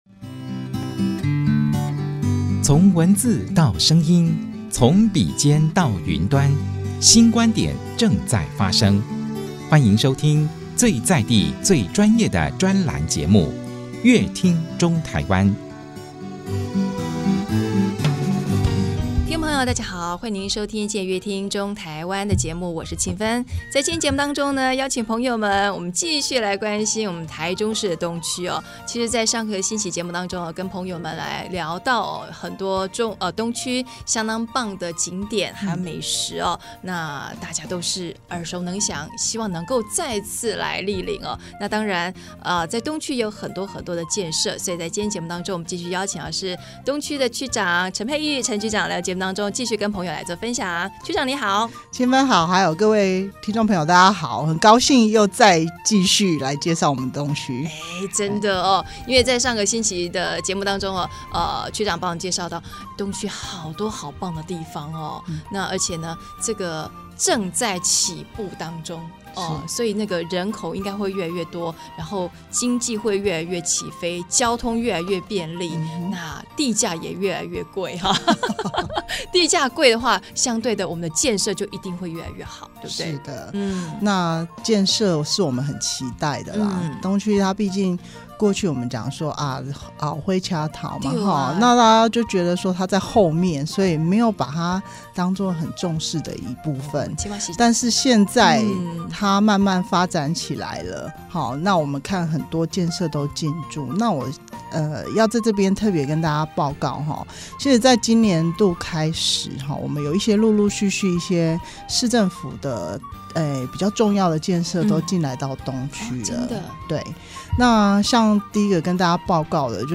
本集來賓：臺中市東區區公所陳佩玉區長 本集主題：「建設翻轉東區印象 」